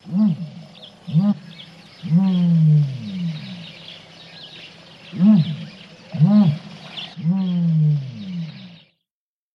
На этой странице вы найдете подборку звуков страуса – от характерного шипения до необычных гортанных криков.
Самец страуса в Намибии